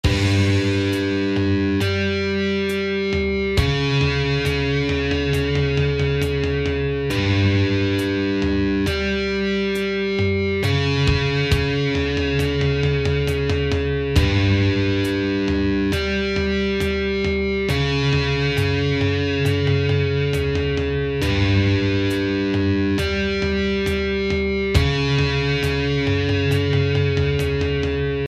Это он в Гитар Про :-D